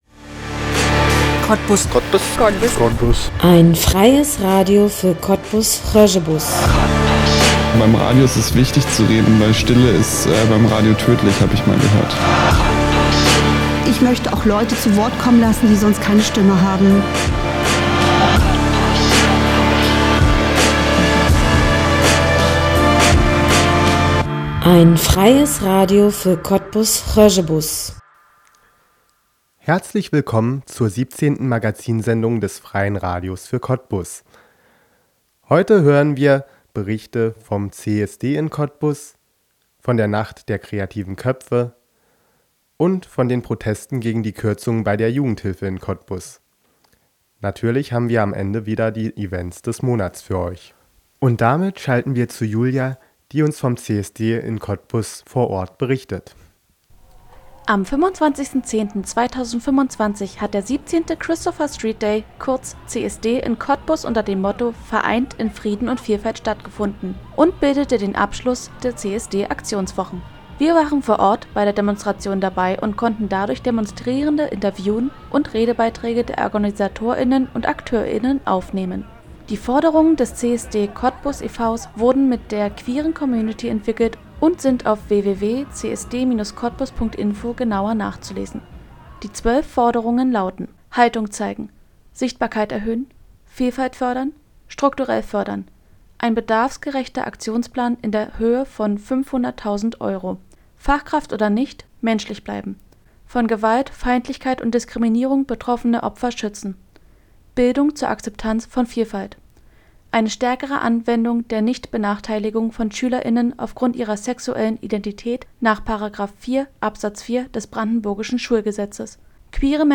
Hier als musikfreie Version zum Nachhören
Hier als musikfreie Version zum Nachhören Inhalt der Sendung: Wir waren am 25.10.2025 auf dem CSD Cottbus unterwegs und haben Stimmen vor Ort eingefangen, was die Menschen bewegt, die CSD Demonstration zu unterstützen, um welche zetrale Themen es ihnen geht und welche Herausforderungen und auch Gegenproteste die Veranstaltung mit sich bringt.
Wir konnten Student:innen der Sozialen Arbeit und betroffene Stimmen aus Sandow und Sachsendorf interviewen.